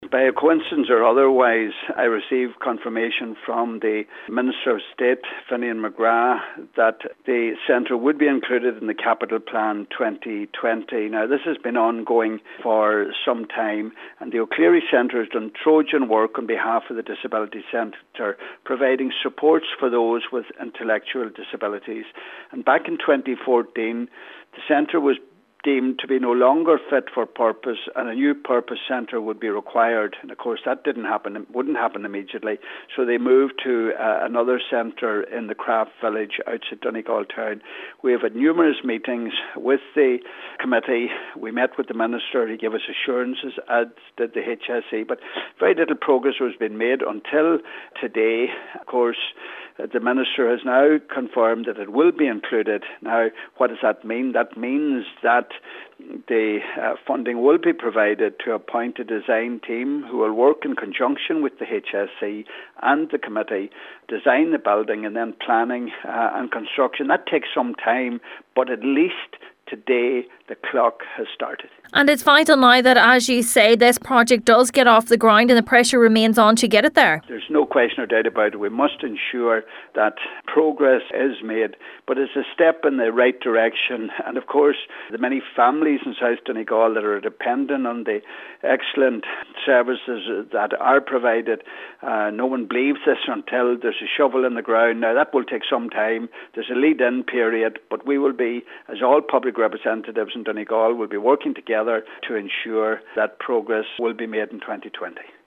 Donegal Deputy Pat the Cope Gallagher has welcomed the funding commitment given by Minister Finian McGrath but says the design team must now be appointed without delay: